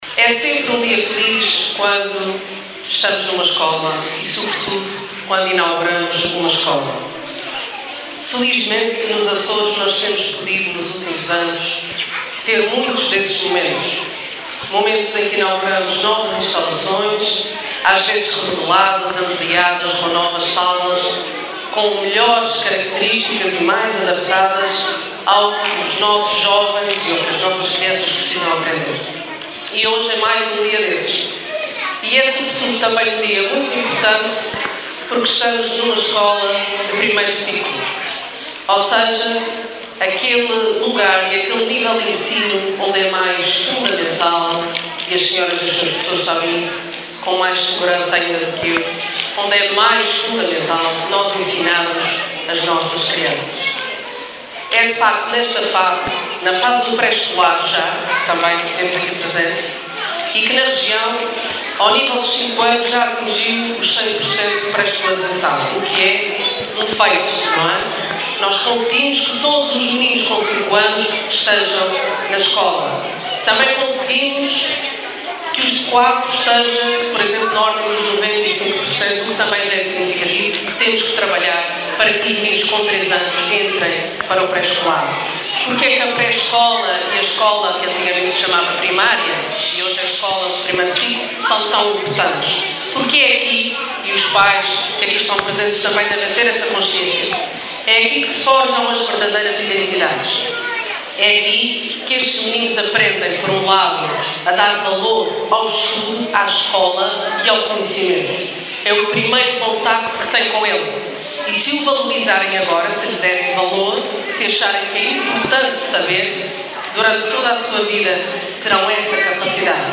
A Secretária Regional da Educação e Formação esteve presente hoje, a convite da Câmara Municipal de Vila Franca do Campo e em representação do Presidente do Governo, na cerimónia de inauguração das obras de remodelação da Escola Básica /JI Padre Manuel Ernesto Ferreira.